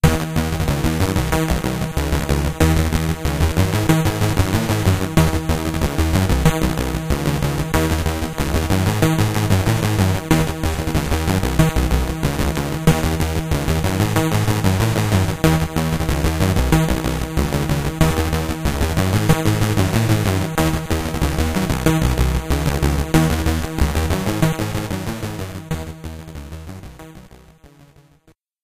Game over tune in OGG format (1.0 Mb)